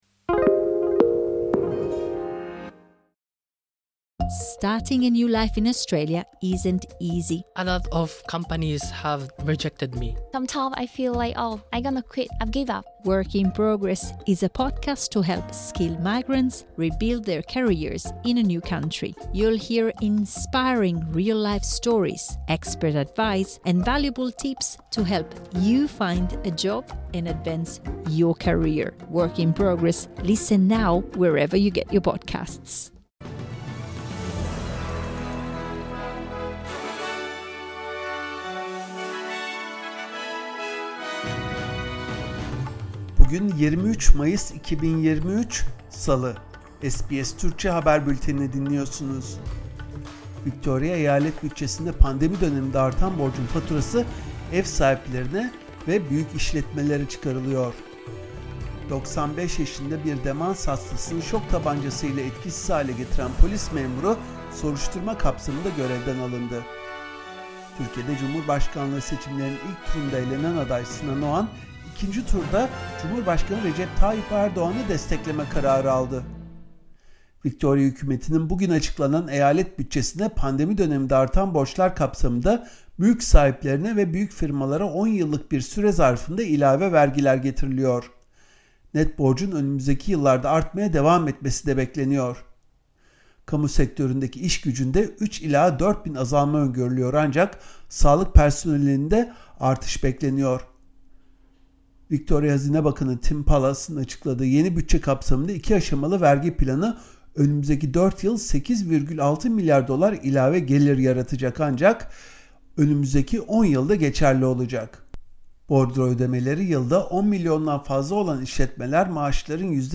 SBS Türkçe Haber Bülteni